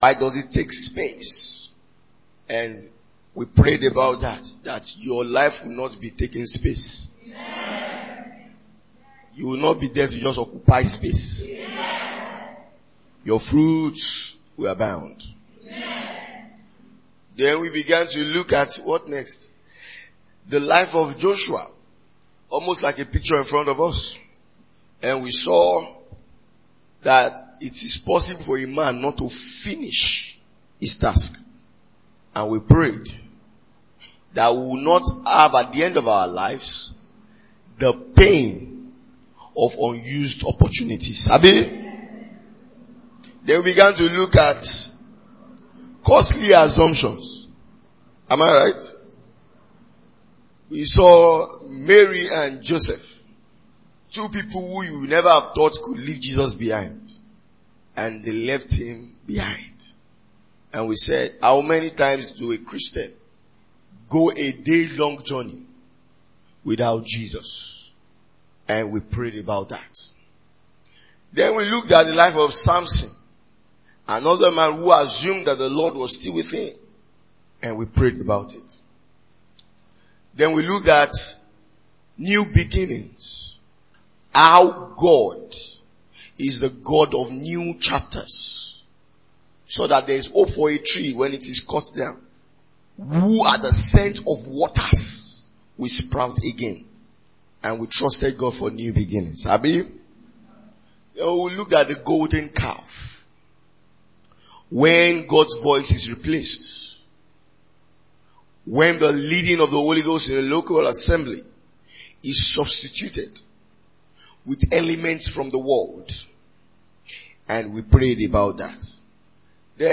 Parting shot from Ministers’ Retreat 2023. It’s a message that encourages the believer to keep all that has been deposited in him aflame.